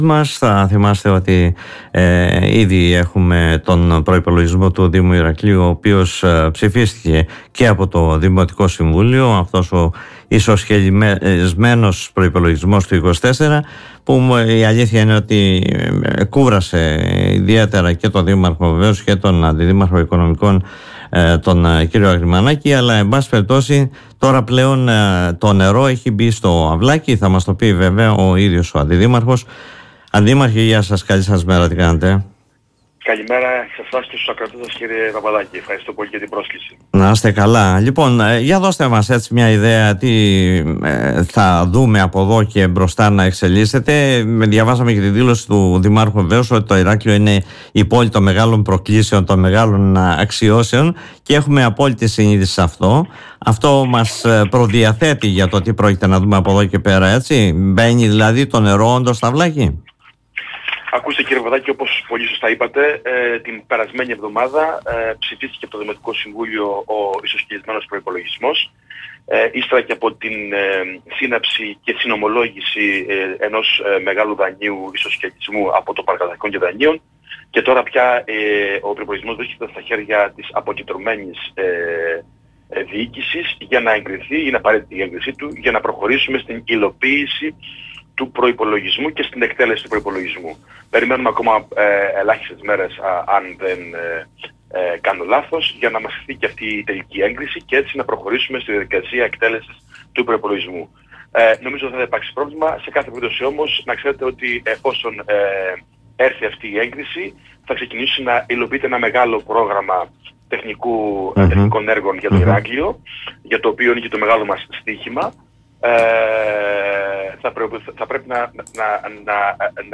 Ο κ. Αγριμανάκης μιλώντας στην εκπομπή “Δημοσίως”